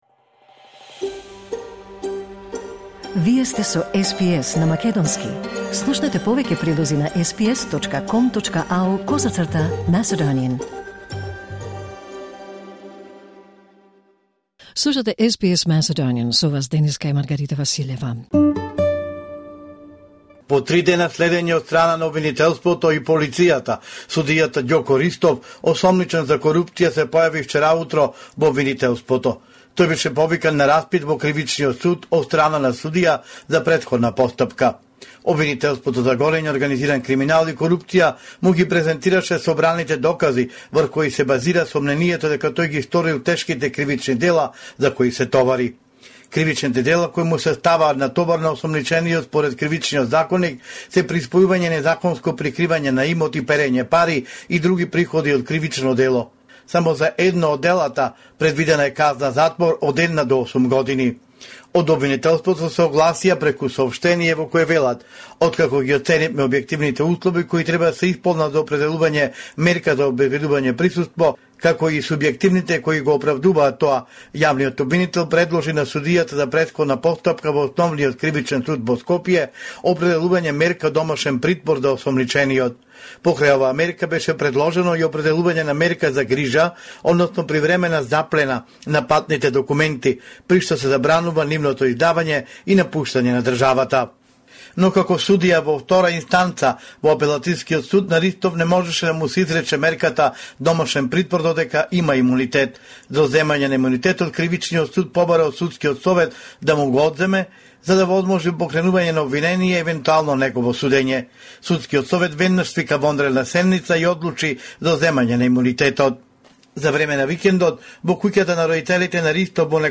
Извештај од Македонија 21 јануари 2026